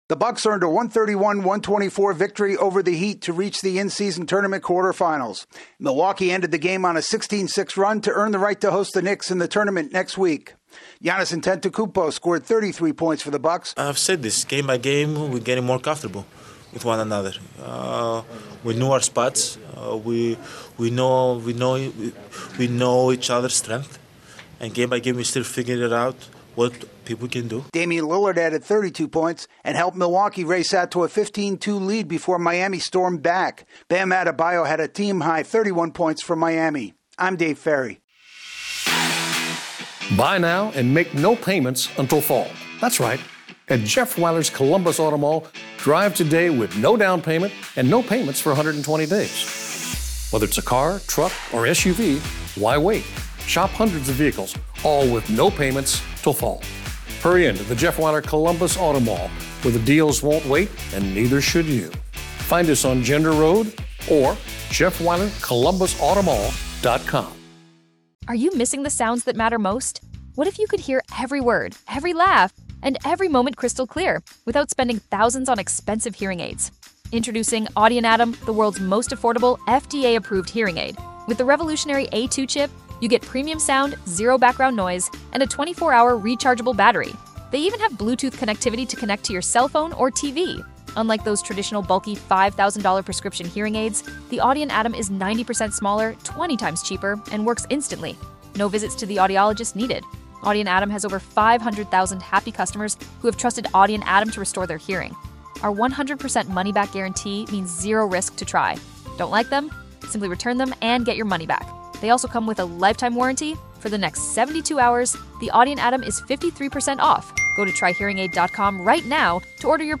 The Bucks use a late run to defeat the Heat. AP correspondent